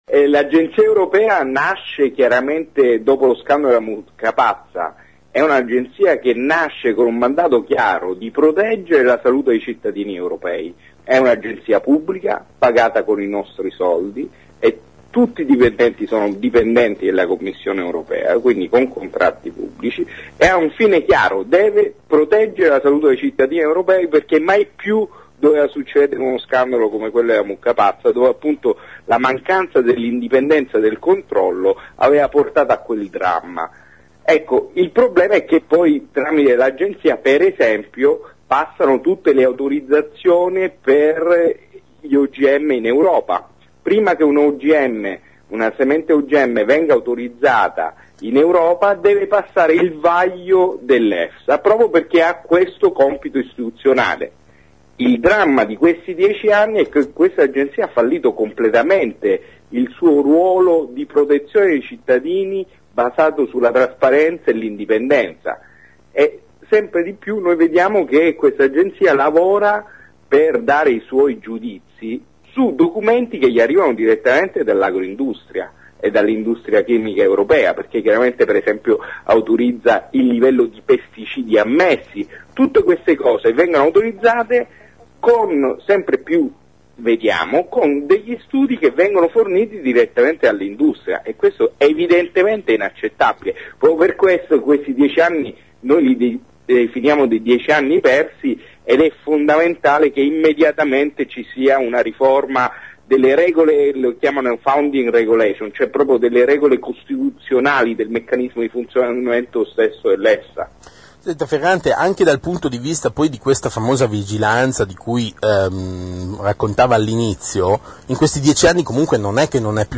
A Km0, la nostra trasmissione green in onda ogni mercoledì, questa mattina abbiamo parlato delle critiche delle associazioni al lavoro svolto dall'Efsa, Agenzia Europea per la sicurezza alimentare che ha sede da 10 anni a Parma. Chi controlla che il suo lavoro sia realmente impermeabili alle pressioni delle industrie agroalimentari? Ci siamo occupati anche di foodshering e di certificazioni energetiche.